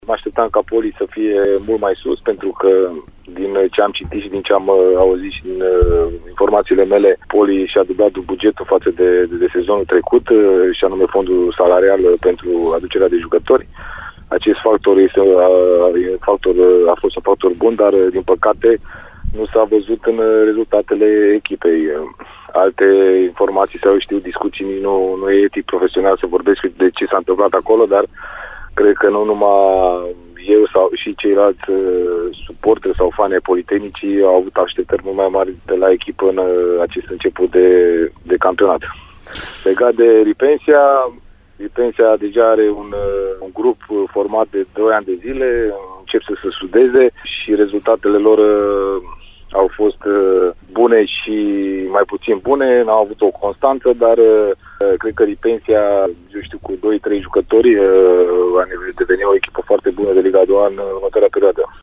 a vorbit într-un interviu pentru Radio Timișoara despre cele două formații timișorene